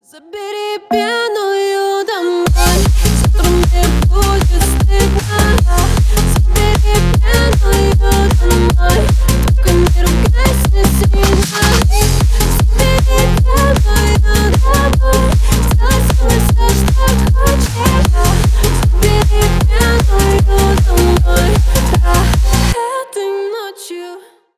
бесплатный рингтон в виде самого яркого фрагмента из песни
Ремикс
клубные